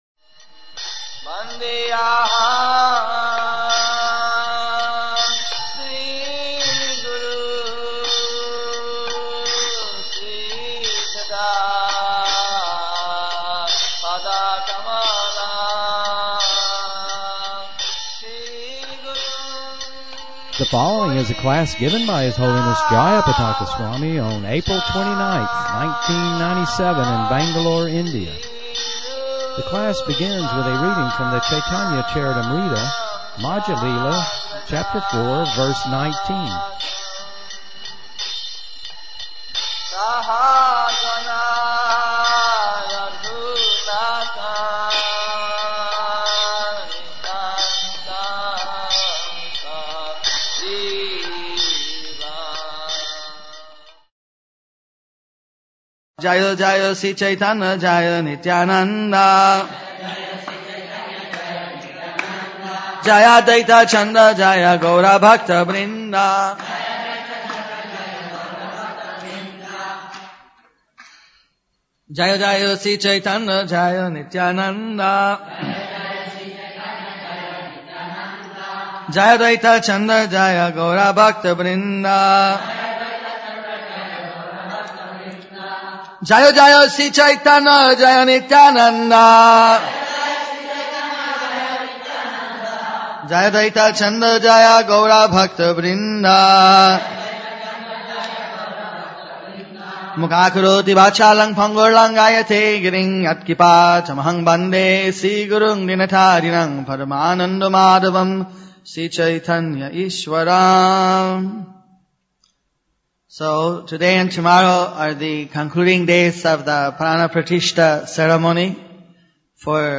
The class begins with a reading from a class on Caitanya Caritamrita, Madhya Lila, Chapter-4, verse 19.